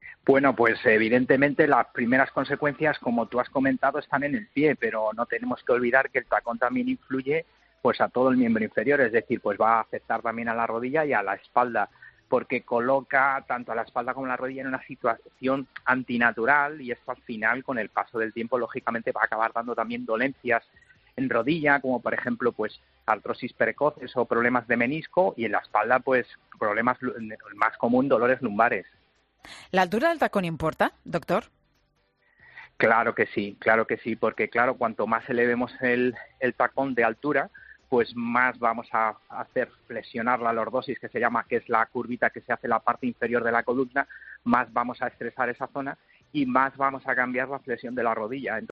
Un doctor advierte en Mediodía COPE sobre las consecuencias del uso de tacones.